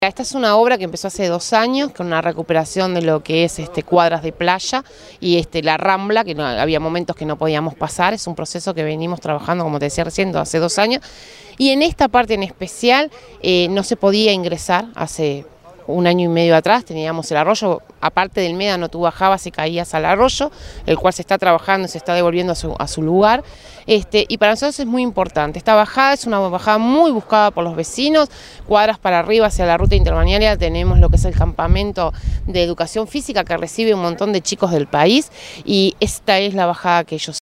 alcaldesa_de_parque_del_plata_tania_vecchio.mp3